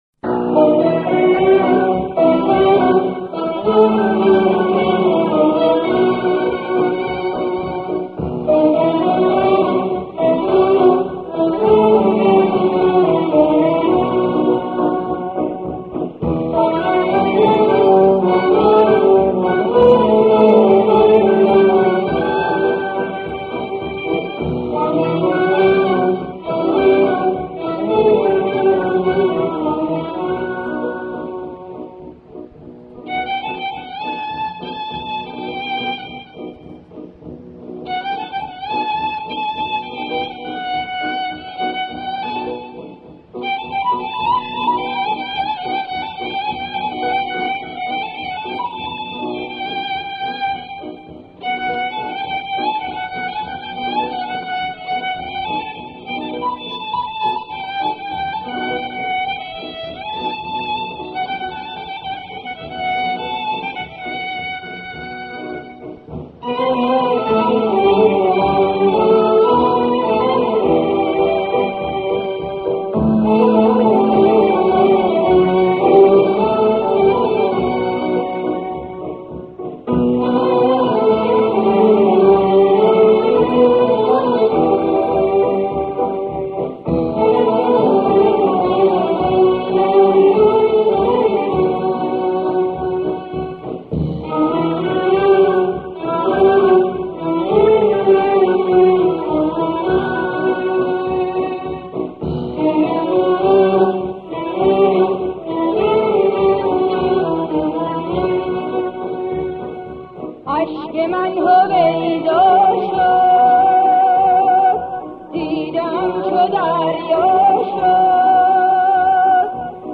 دستگاه / مقام: بیداد همايون